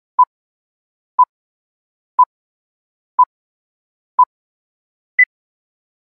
دانلود آهنگ ثانیه شمار 7 از افکت صوتی اشیاء
دانلود صدای ثانیه شمار 7 از ساعد نیوز با لینک مستقیم و کیفیت بالا
جلوه های صوتی